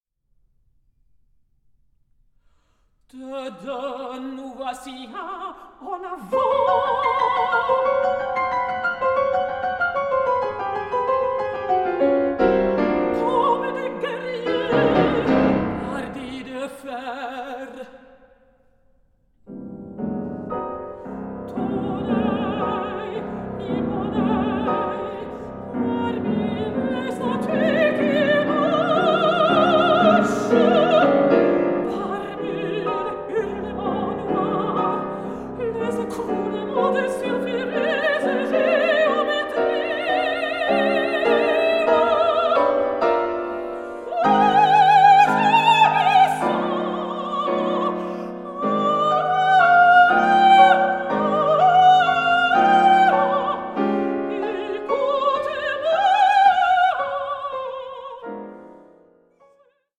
mezzo-soprano
pianist